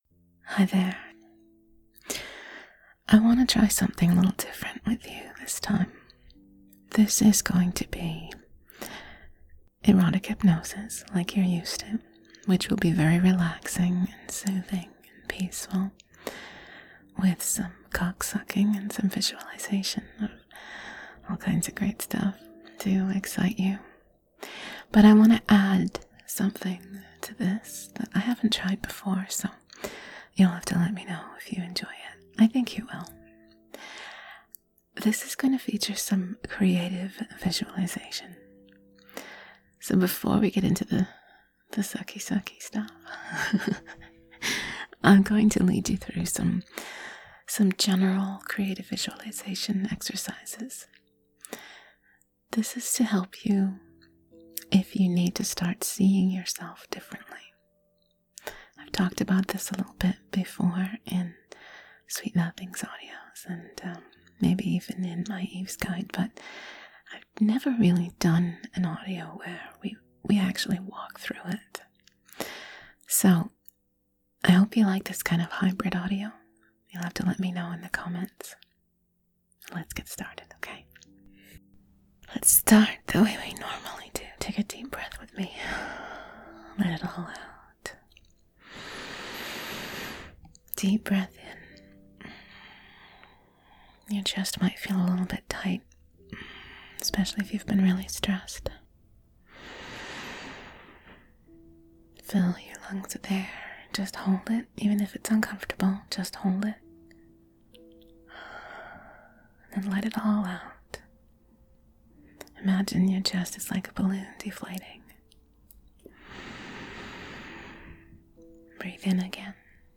Its absolutely relaxing and calm I had a great feel.